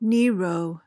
PRONUNCIATION: (NEE-ro) MEANING: noun: A cruel, depraved, or tyrannical ruler.